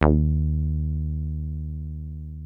303 D#2 2.wav